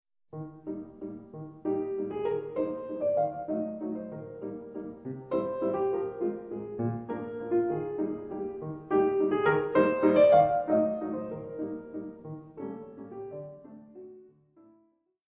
mp3Tchaikovsky, Pyotr Ilyich, Album for the Young, No. 9, Waltz, mm.1-13